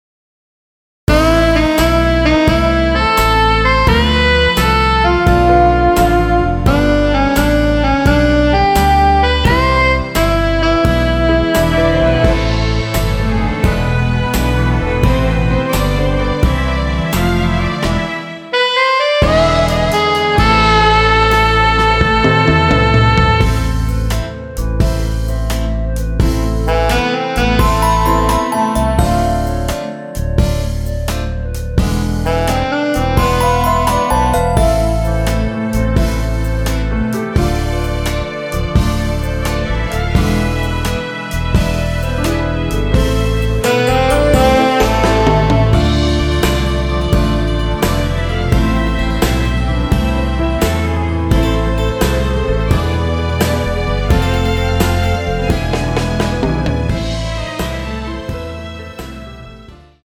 원키에서(-6)내린 멜로디 포함된 MR입니다.(미리듣기 확인)
Am
노래방에서 노래를 부르실때 노래 부분에 가이드 멜로디가 따라 나와서
앞부분30초, 뒷부분30초씩 편집해서 올려 드리고 있습니다.
중간에 음이 끈어지고 다시 나오는 이유는